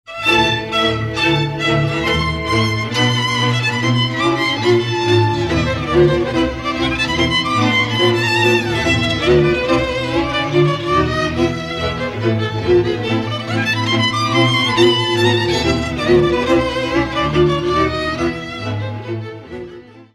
Dallampélda: Hangszeres felvétel
Erdély - Szilágy vm. - Szilágybagos
kontrás (Szilágybagos, Szilágy vm.), brácsa
bőgős, bőgő
Műfaj: Lassú csárdás
Stílus: 1.1. Ereszkedő kvintváltó pentaton dallamok